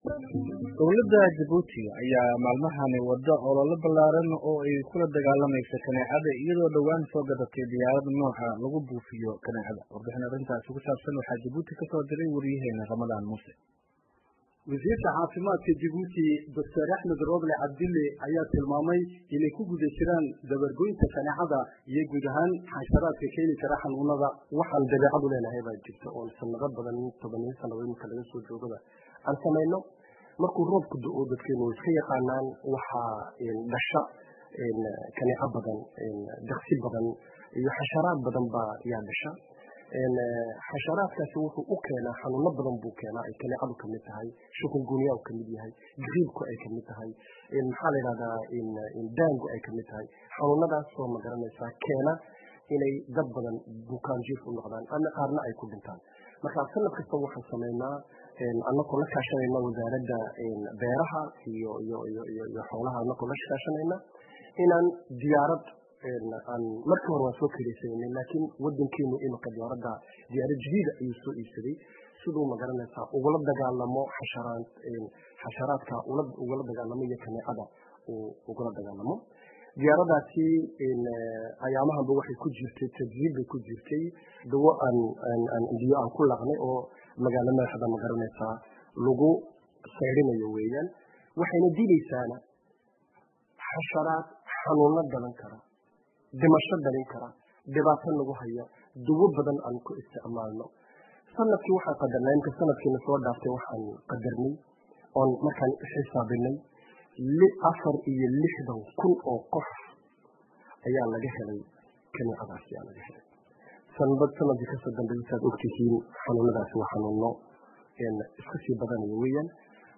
Djibouti —